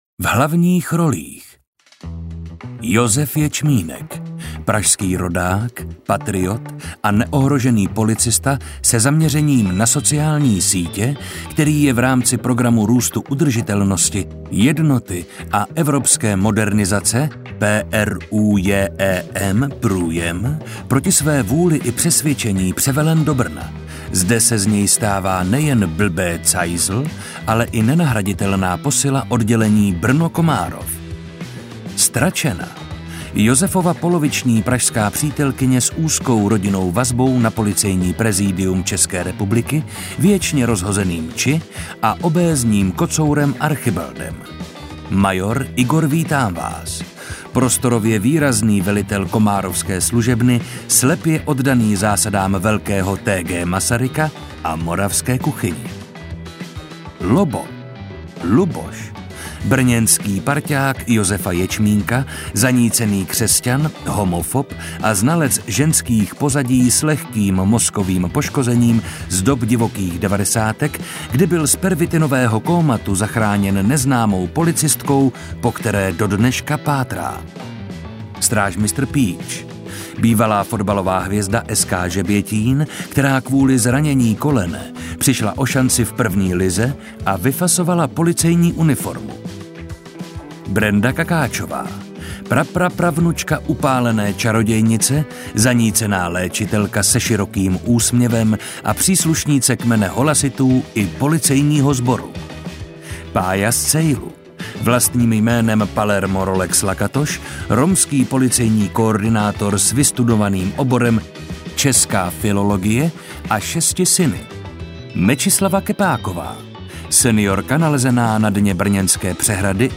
Interpret:  Marek Holý
AudioKniha ke stažení, 44 x mp3, délka 8 hod. 8 min., velikost 443,7 MB, česky